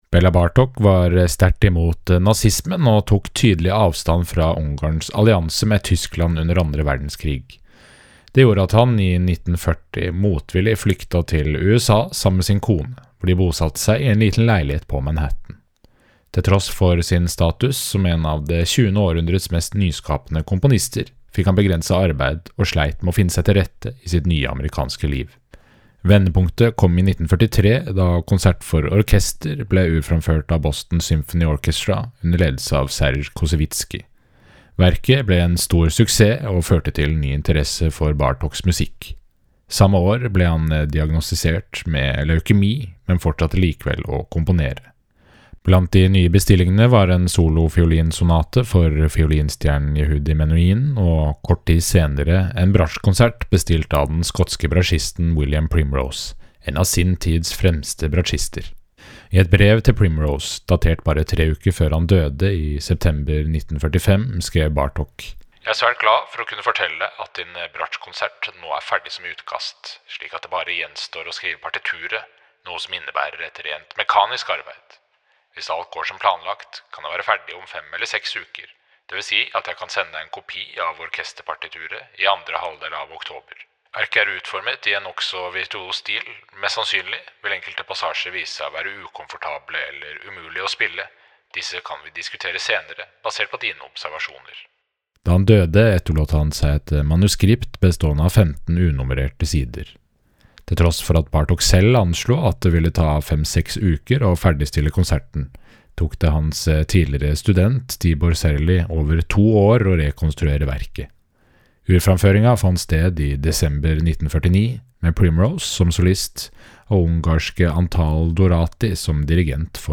VERKOMTALE-Bela-Bartoks-Bratsjkonsert.mp3